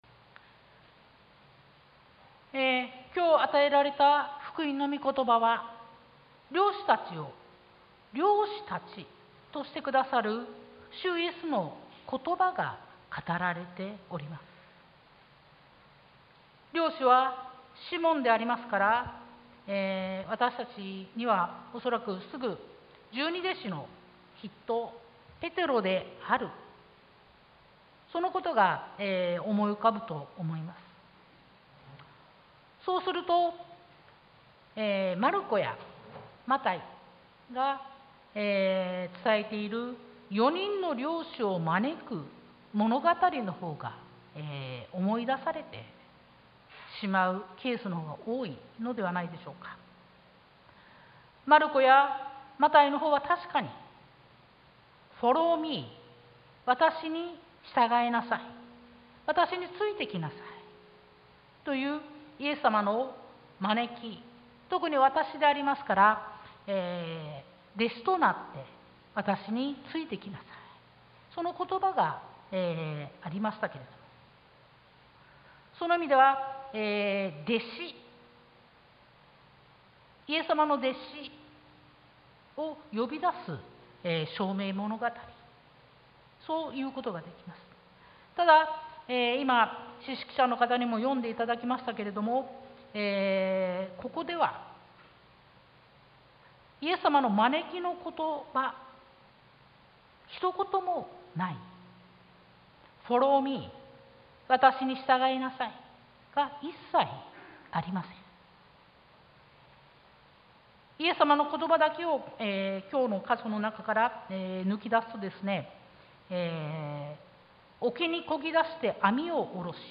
sermon-2022-07-03